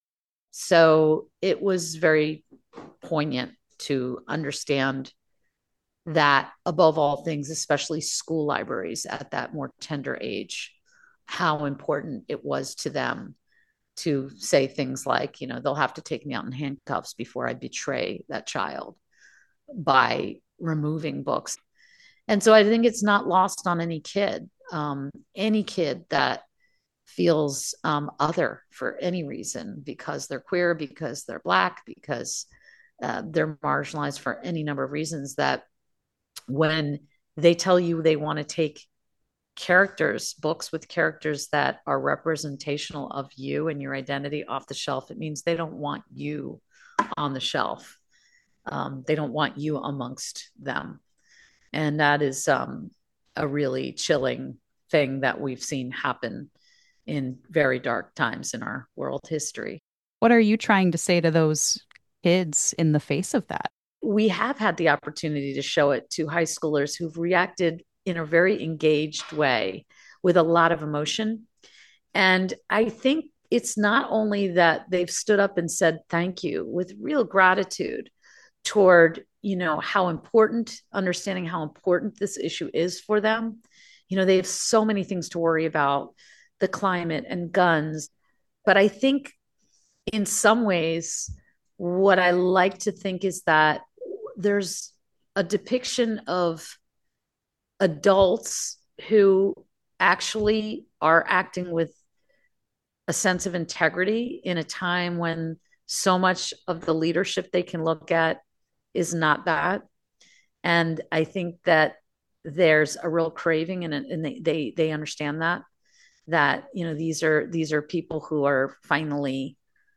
Their conversation has been lightly edited for clarity.
interview